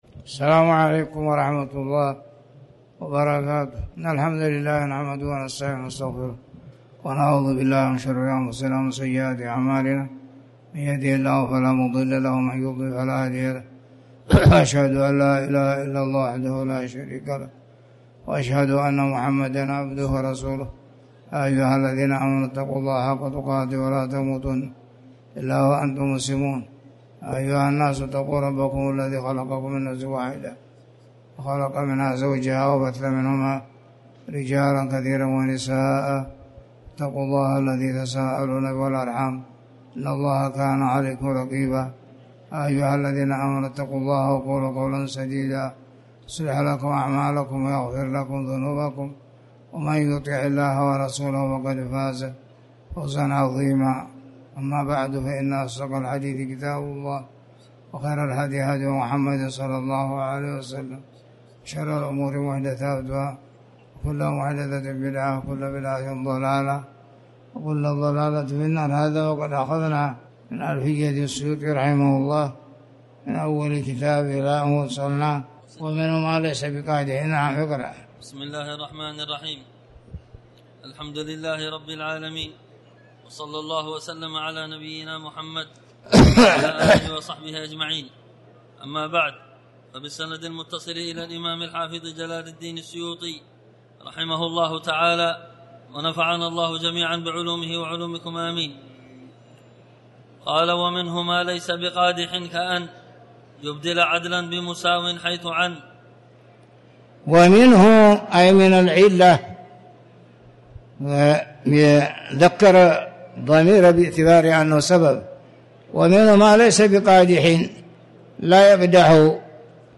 تاريخ النشر ٦ صفر ١٤٤٠ هـ المكان: المسجد الحرام الشيخ